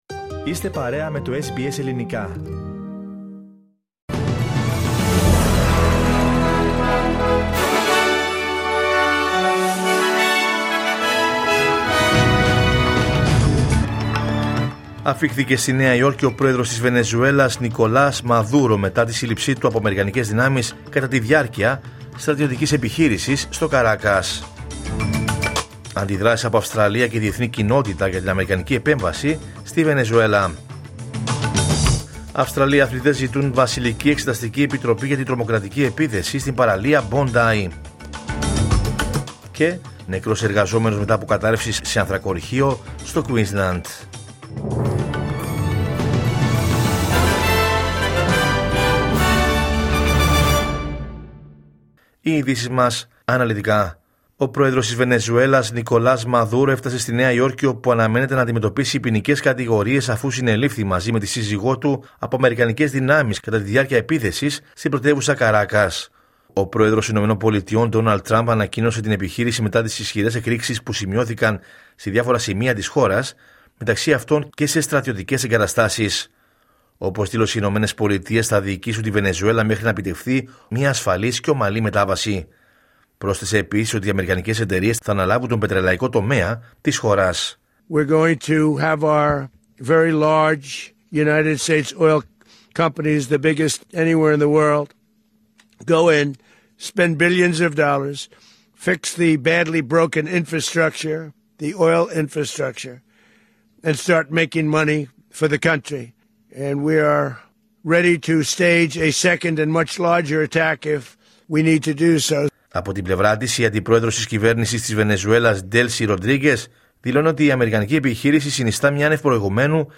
Ειδήσεις από την Αυστραλία, την Ελλάδα, την Κύπρο και τον κόσμο στο Δελτίο Ειδήσεων της Κυριακής 4 Ιανουαρίου 2026.